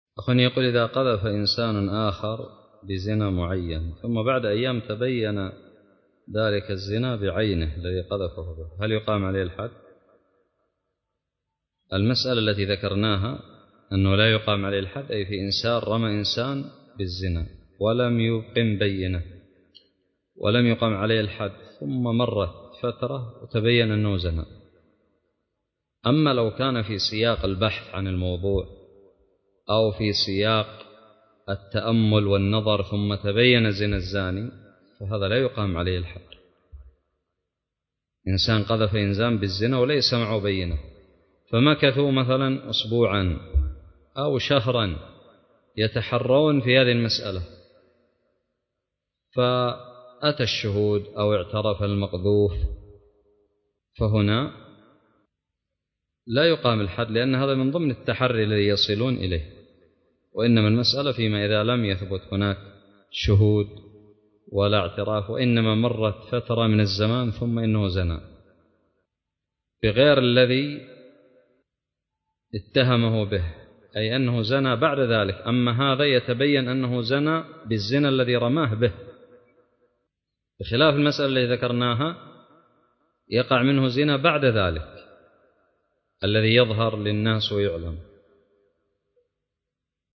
:العنوان فتاوى الحدود والديات :التصنيف 1444-9-8 :تاريخ النشر 197 :عدد الزيارات البحث المؤلفات المقالات الفوائد الصوتيات الفتاوى الدروس الرئيسية إذا قذف إنسان أخر بالزنا ثم تبين ذلك الزنا بعينة هل يقام عليه الحد ؟